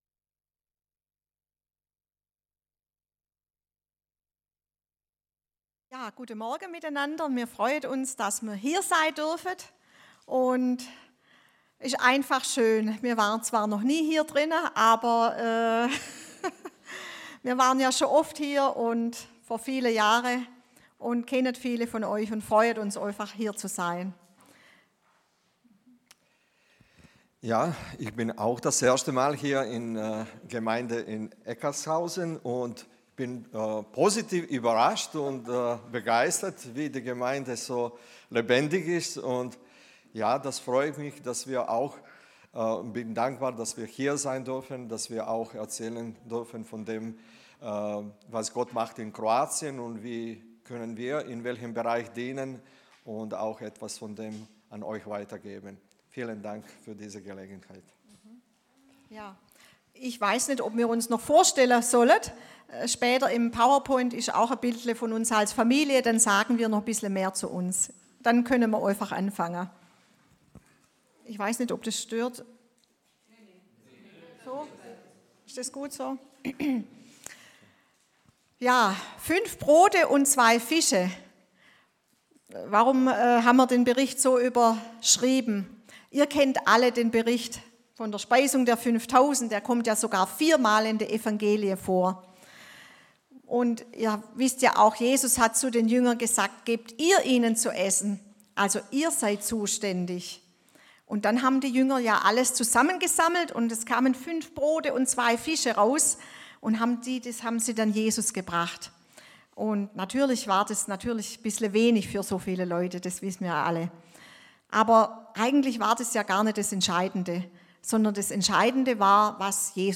Predigten der verschiedenen Menschen, die im Gottesdienst mal anders predigen.